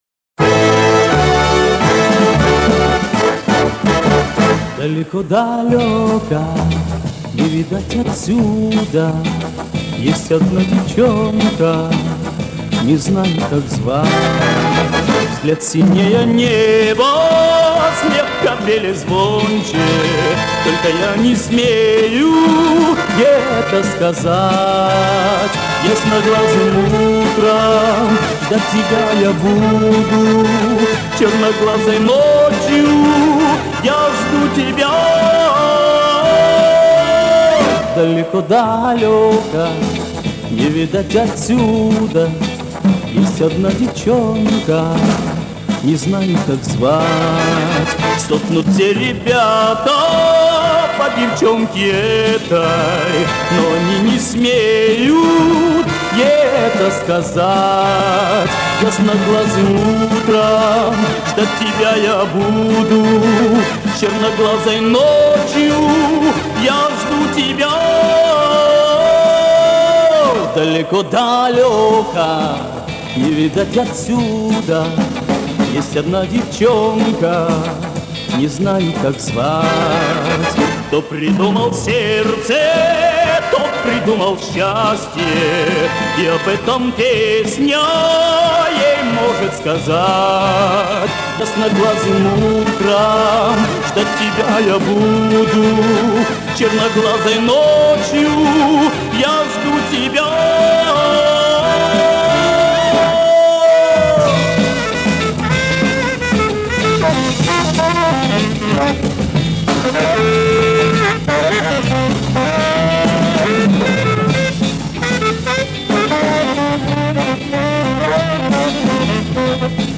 да похоже на облетевшую магнитную ленту.